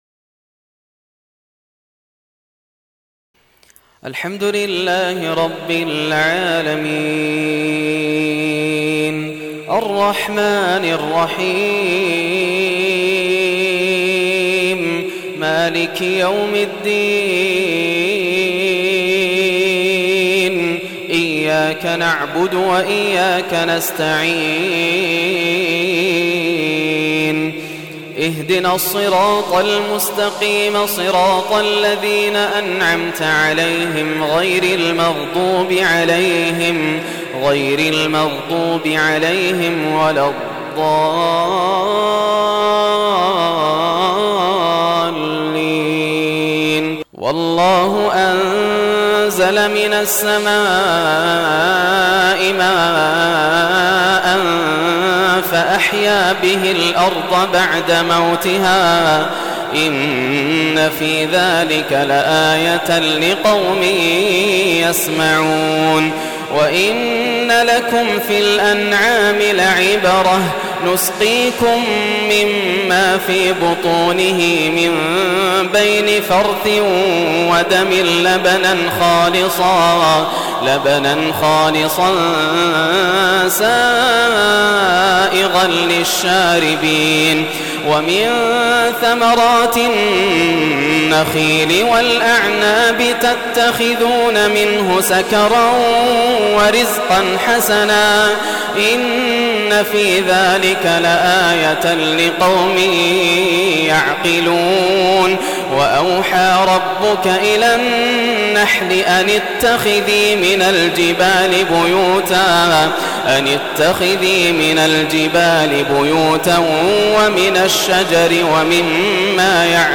تلاوة خاشعة من سورة النحل - 1429 > عام 1429 > الفروض - تلاوات ياسر الدوسري